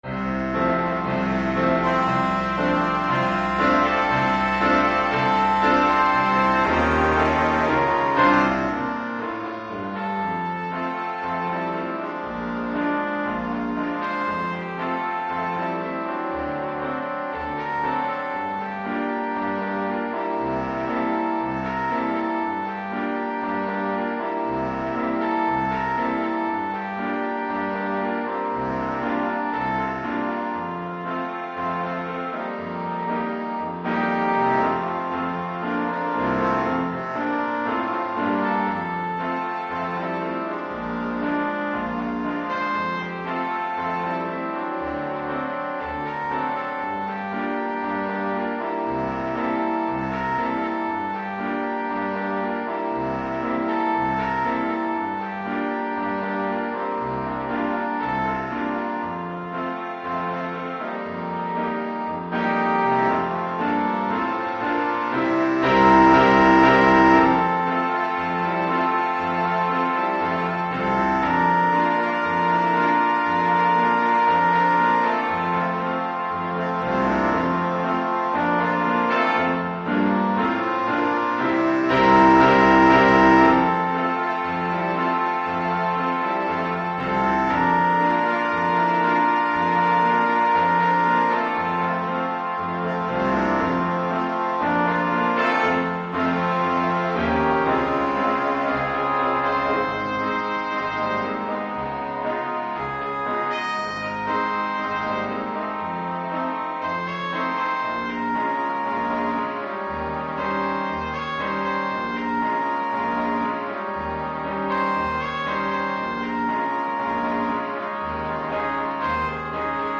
Gattung: 5-Part Ensemble
Besetzung: Ensemble gemischt
Keyboard, Drums & Percussions optional.